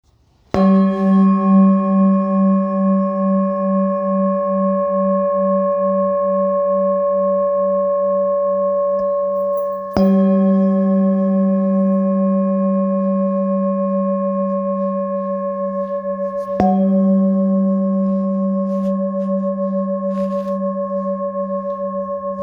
Handmade Singing Bowls-31779
Singing Bowl, Buddhist Hand Beaten, with Fine Etching Carving, Mandala, Select Accessories
Material Seven Bronze Metal